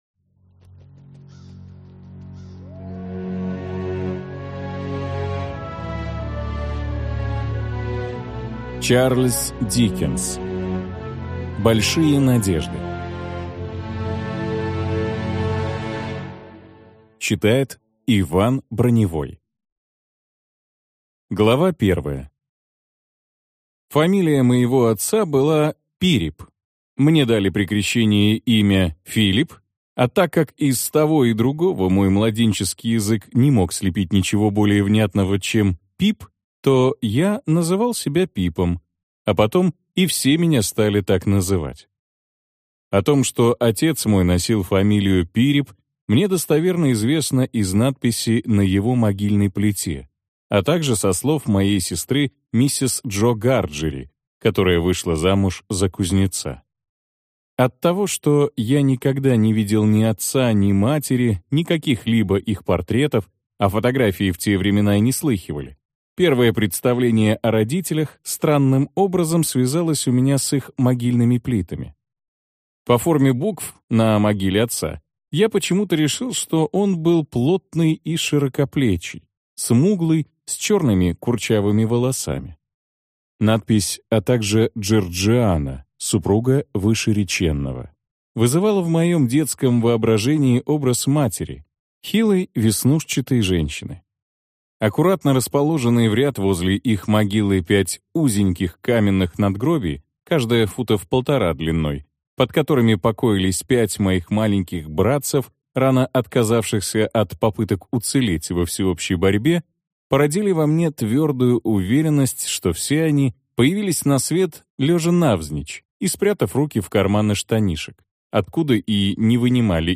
Аудиокнига Большие надежды | Библиотека аудиокниг